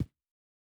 Shoe Step Stone Hard D.wav